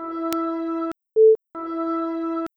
There are just seven anomalous samples making a very audible click.
By way of comparison, this is the “Repair” effect (before and after):